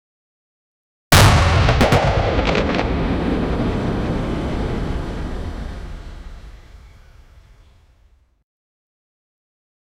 Royalty-free cannon sound effects
one shot of a cannon for a 2d game, make it short and simple
one-shot-of-a-cannon-5k3cbg4x.wav